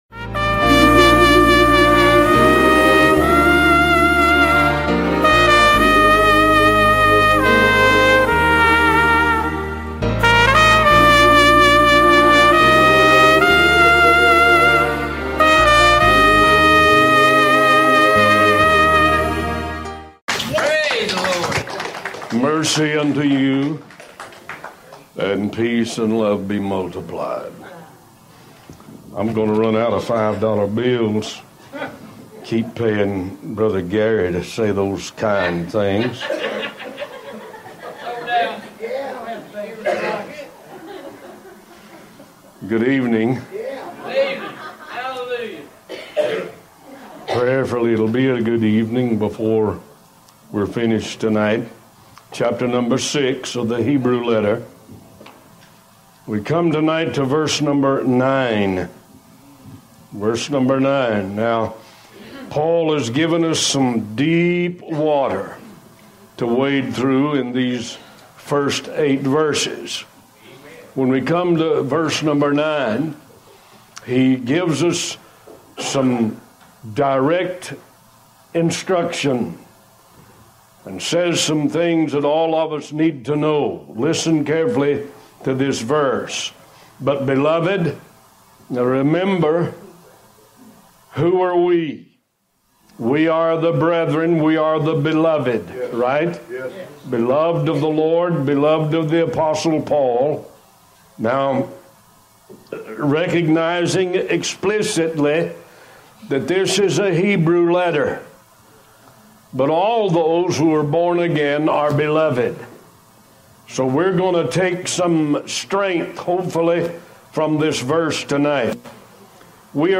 Talk Show Episode, Audio Podcast, One Voice and The Hebrews Letter continued on , show guests , about The Hebrews Letter continued, categorized as History,Philosophy,Religion,Christianity,Society and Culture